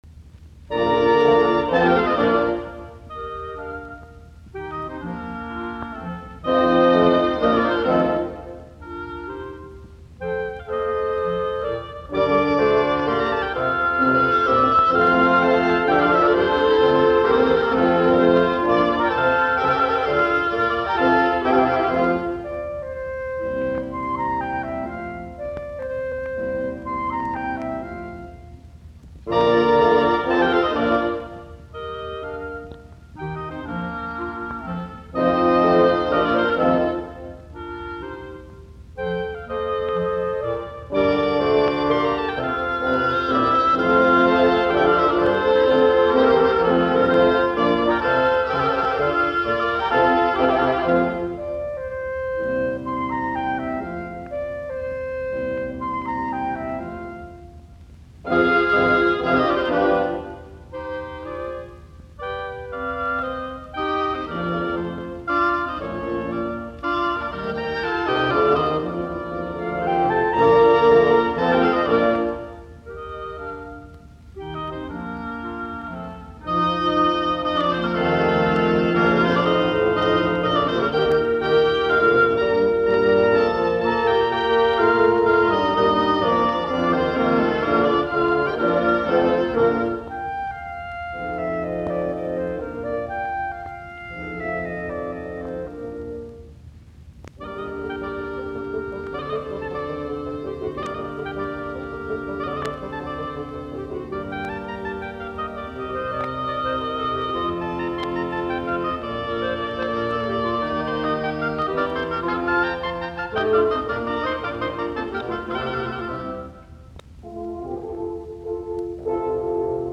puhallinork.
B-duuri